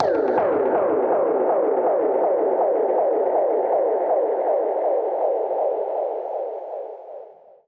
Index of /musicradar/dub-percussion-samples/125bpm
DPFX_PercHit_A_125-04.wav